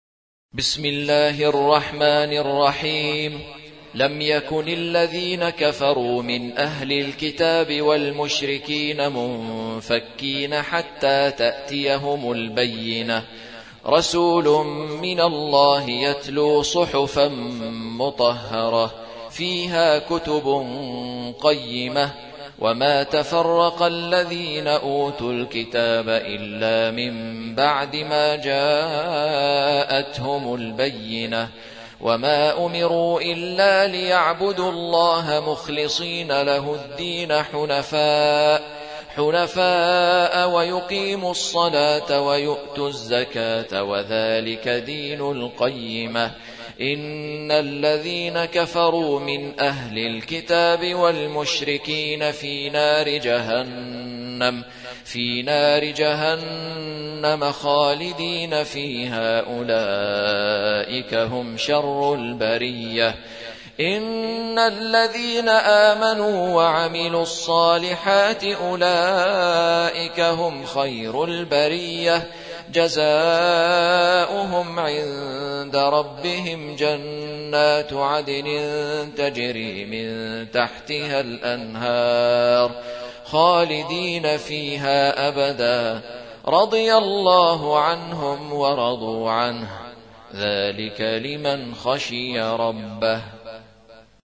سورة البينة / القارئ